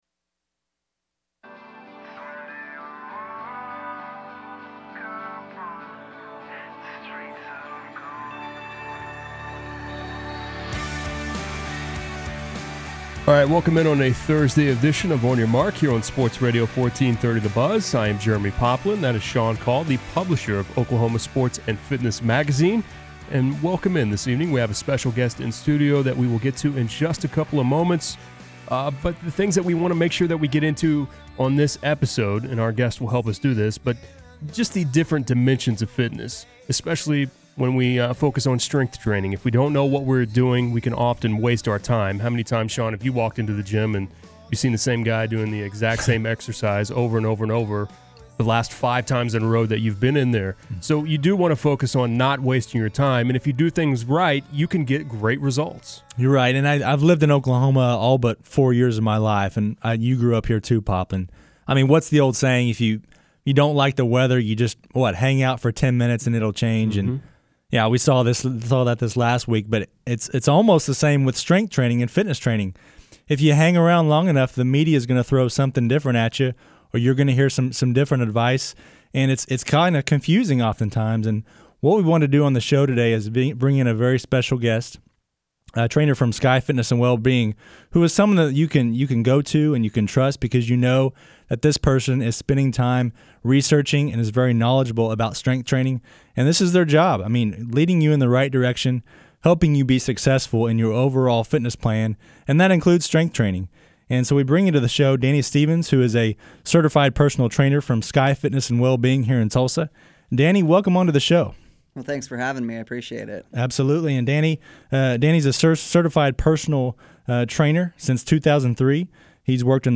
Thursdays at 6:00 pm on AM 1430 the Buzz in Tulsa, join us for exclusive interviews, fitness advice, and the latest on upcoming events throughout the state..